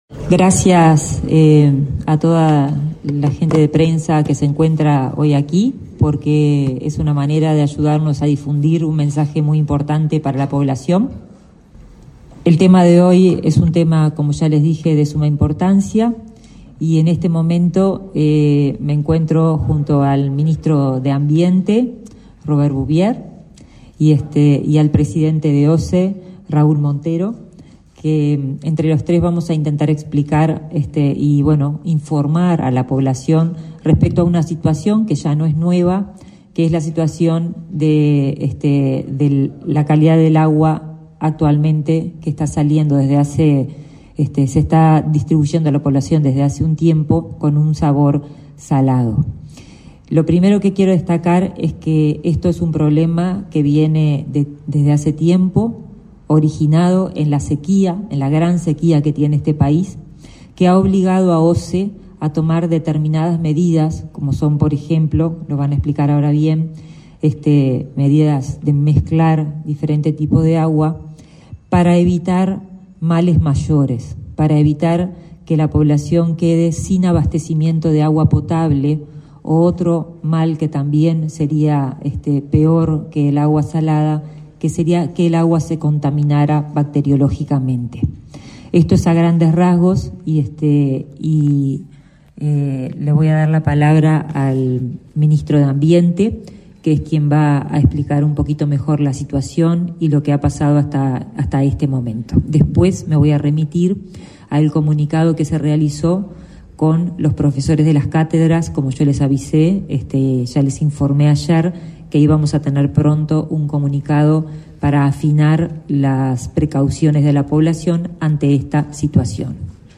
Conferencia de prensa de los ministros de Salud Pública y de Ambiente
Conferencia de prensa de los ministros de Salud Pública y de Ambiente 09/06/2023 Compartir Facebook X Copiar enlace WhatsApp LinkedIn La ministra de Salud Pública, Karina Rando, y el ministro de Ambiente, Robert Bouvier, participaron, este 9 de mayo, en una conferencia de prensa para informar sobre el consumo de agua potable de OSE en Montevideo, Canelones y la zona metropolitana.